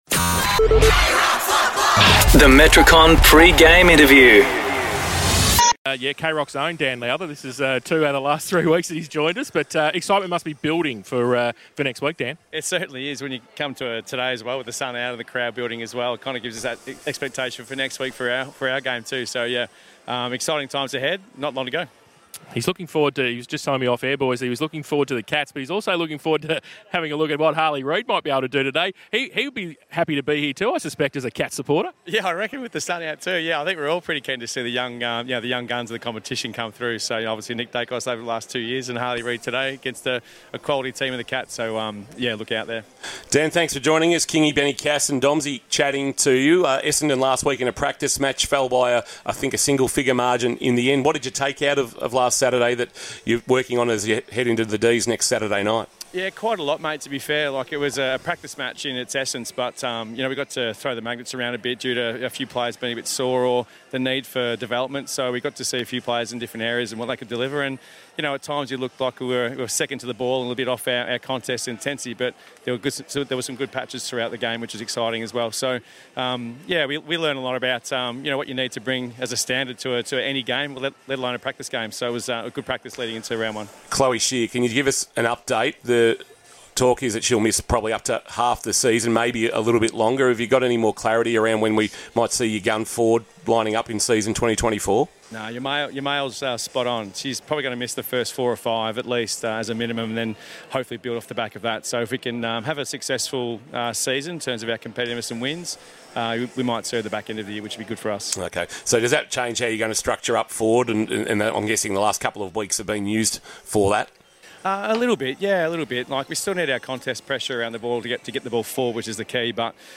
2024 - AFL - Round 24 - Geelong vs. West Coast - Pre-match interview